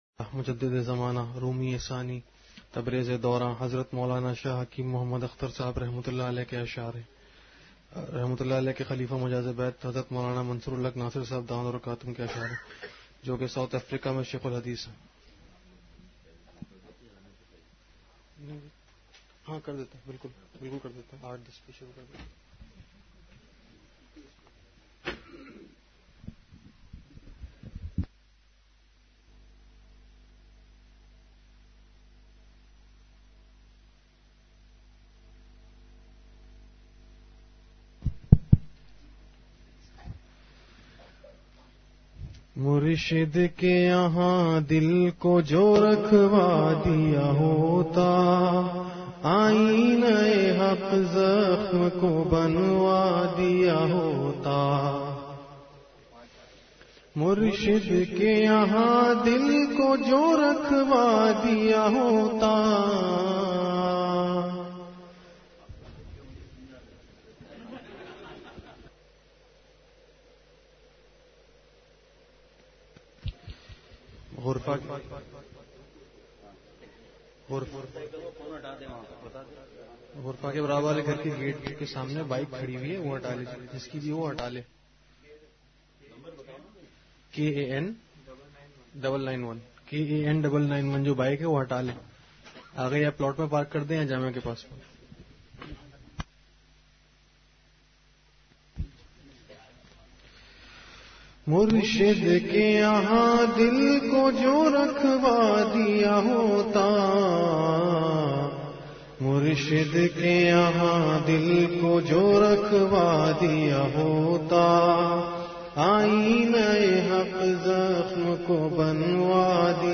مجلس کے آخر میں حضرت شیخ دامت برکاتہم نے ایک پرمزاح لطیفہ سناکر سب احباب کو خوب خوش فرمادیا۔آج کی مجلس کا کُل دورانیہ ایک گھنٹہ ۱۶ منٹ رہا۔